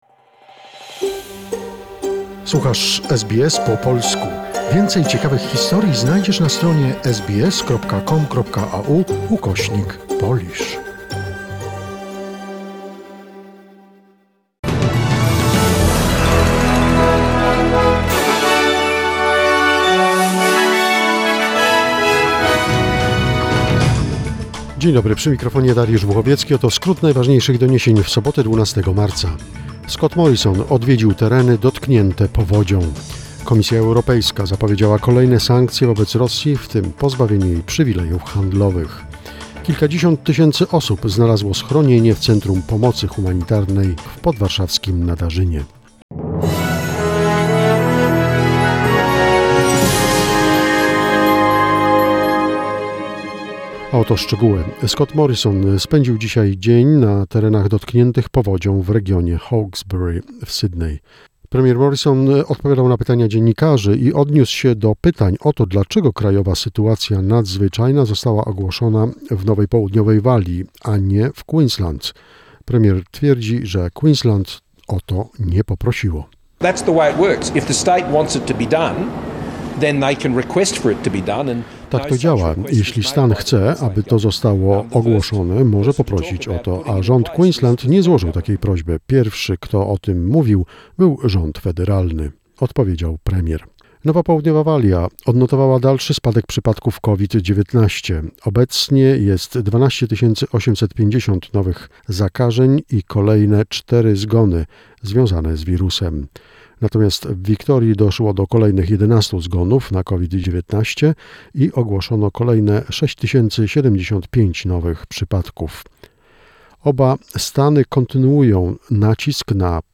SBS News Flash in Polish, 12 March 2022